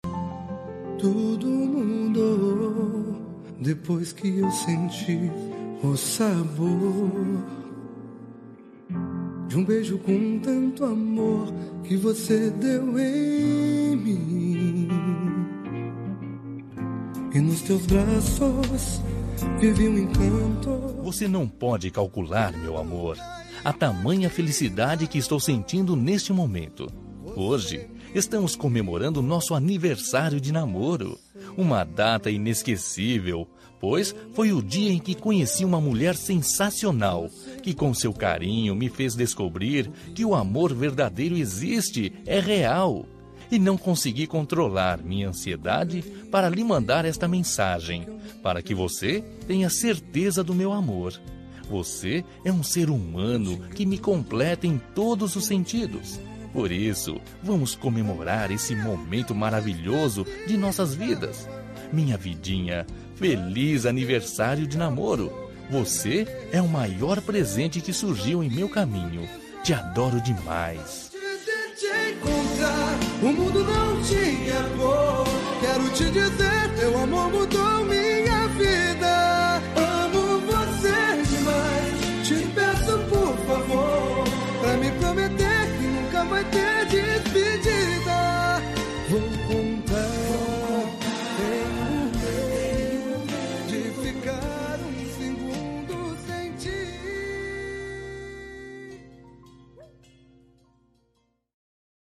Telemensagem Aniversário de Namoro – Voz Feminina – Cód: 80929